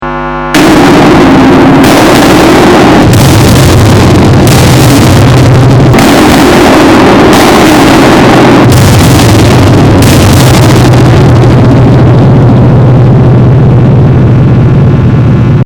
the thunder box donsharizer (tb) is an effect pedal which can make the "donshari" sound.
its a combination of two words; "don", meaning a low kind of sound, and "shari" meaning a high sound as this pedal selectively boosts only low and high frequencies.
the thunder box - donsharizer (tb), however, focuses on boosting only trebel and bass, so unwanted sounds will not pass through the circuit.